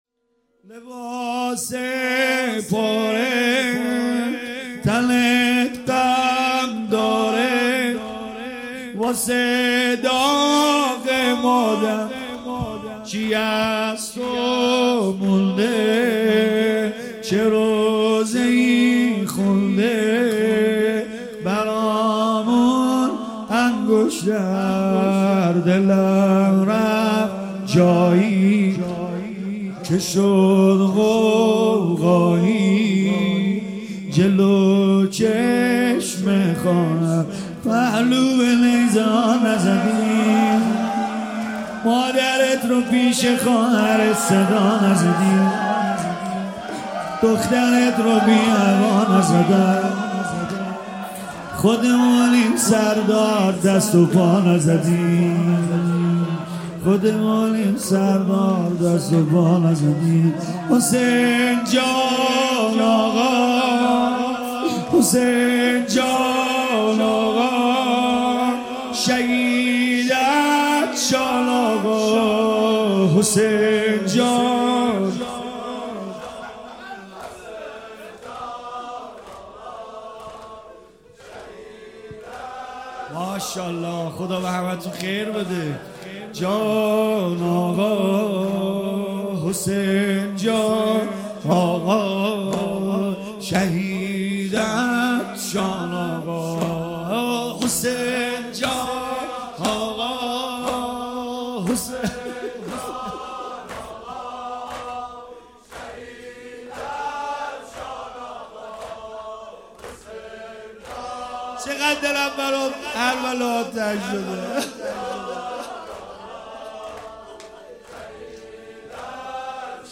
مداحی جدید کربلایی سید رضا نریمانی شب پنجم ماه رمضان ۱۳۹۸ هیات فداییان حسین علیه السلام اصفهان